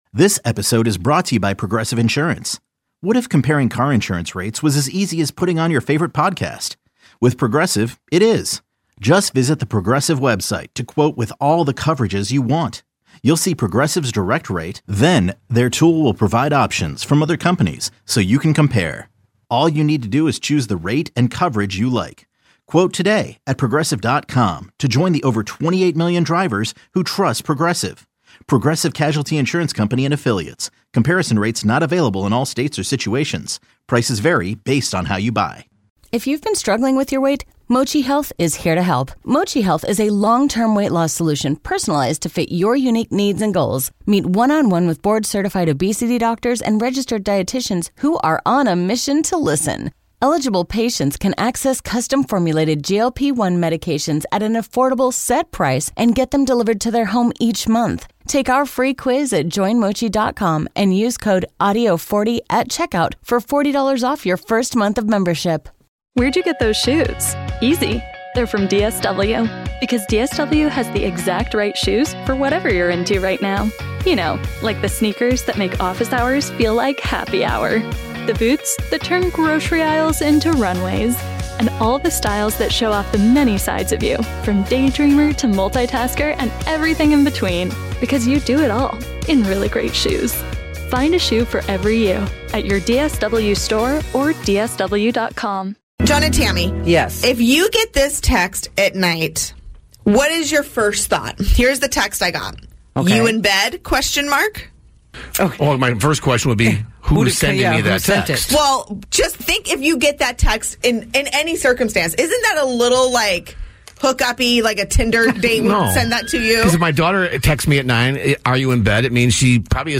Interviews, favorite moments